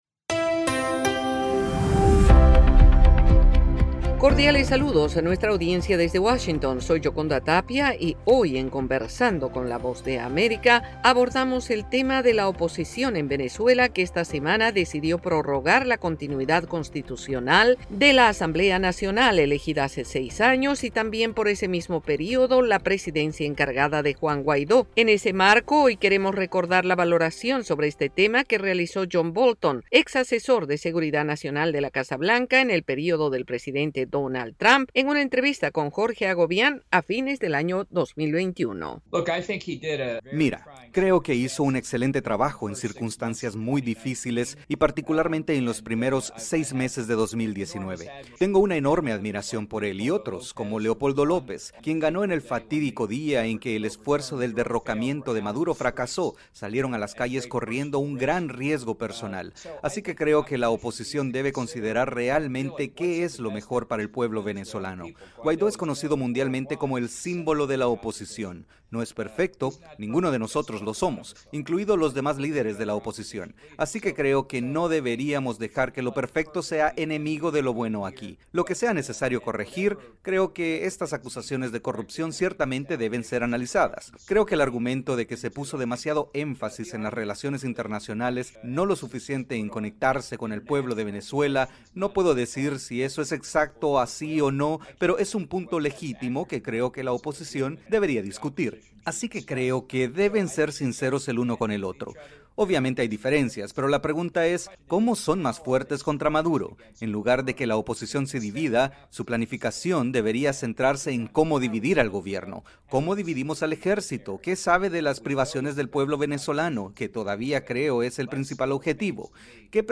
Conversamos con John Bolton, exasesor de seguridad nacional de la Casa Blanca en la presidencia de Donald Trump valorando la situación de la oposición venezolana en una entrevista a fines de 2021.